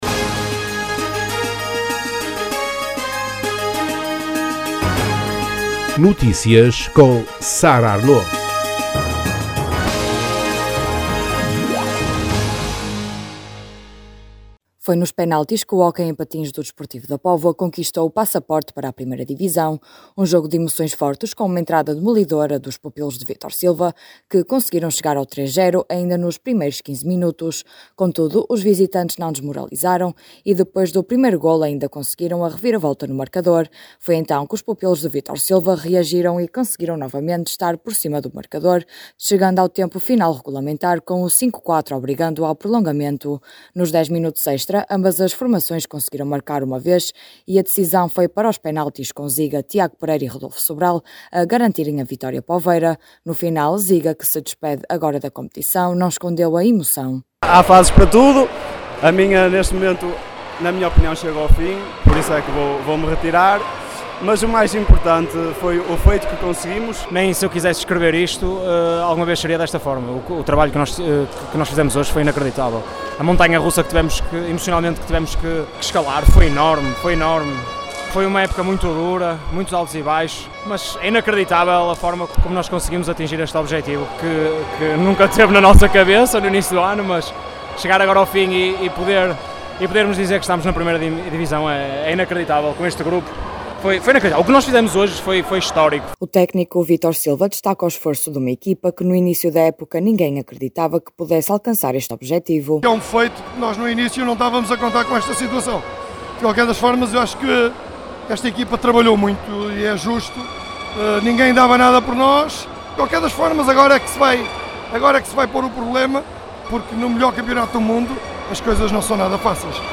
Dez anos depois, eis que o hóquei poveiro volta a ganhar o estatuto de Primeira. As declarações podem ser ouvidas na edição local.